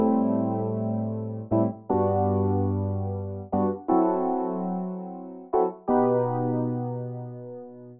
34 ElPiano PT2.wav